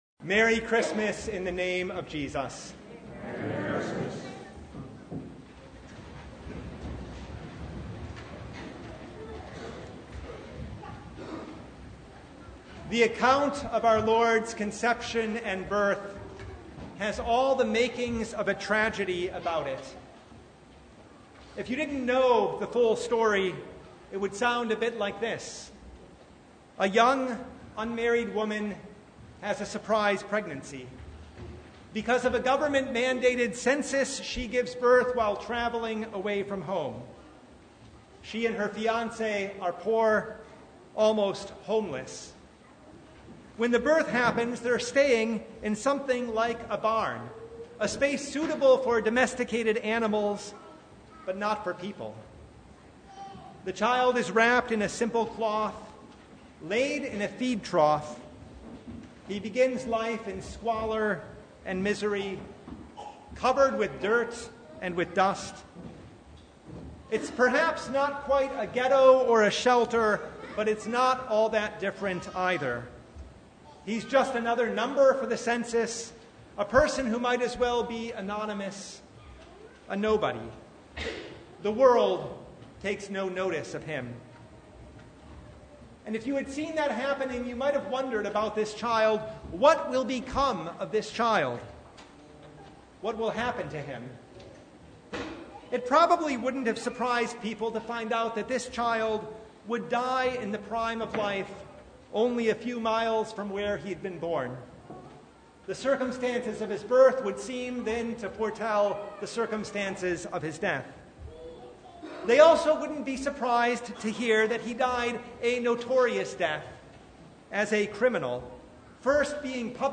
Luke 2:1-14 Service Type: Christmas Eve Vespers With the birth of this Child